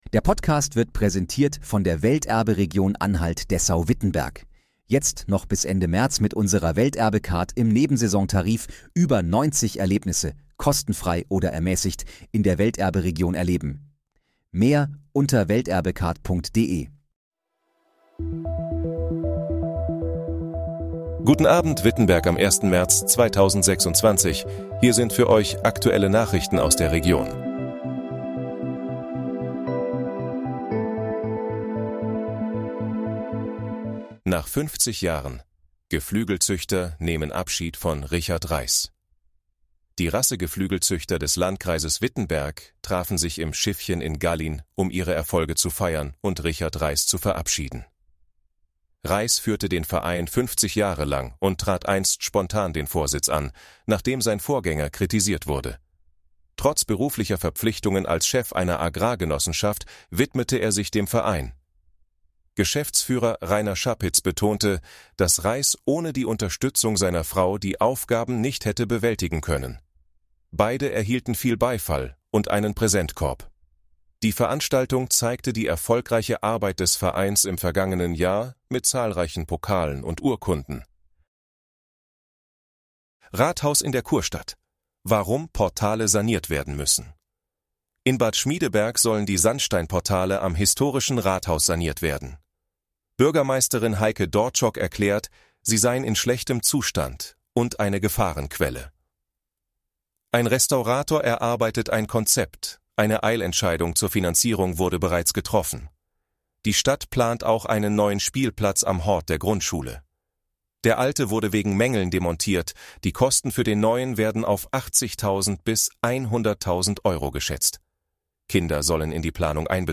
Guten Abend, Wittenberg: Aktuelle Nachrichten vom 01.03.2026, erstellt mit KI-Unterstützung